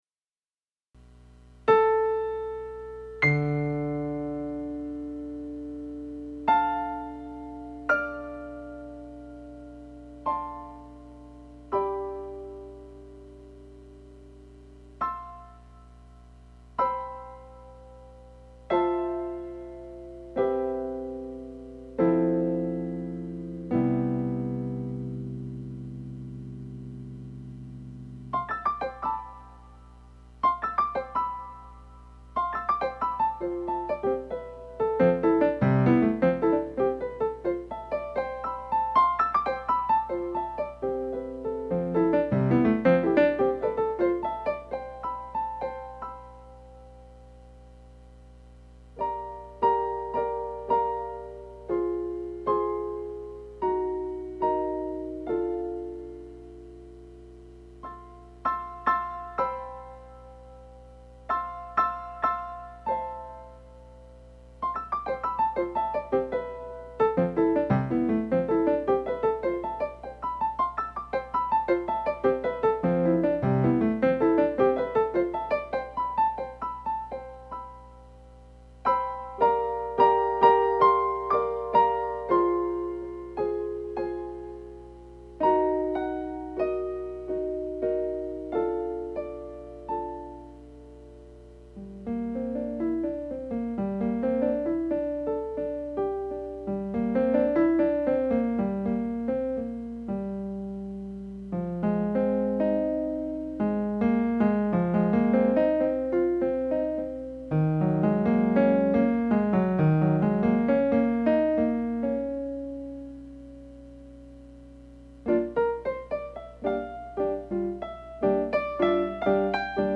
Je m’installe au piano…. 19h25….
Ceci est quand même une pratique surtout de la mécanique pour mettre en ligne rapidement…. La pièce qui suit est le parfait exemple d’une PRTK menant nulle part…
PIANO